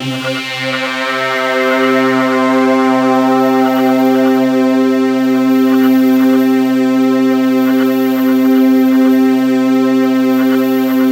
Index of /90_sSampleCDs/Infinite Sound - Ambient Atmospheres/Partition C/07-RANDOMPAD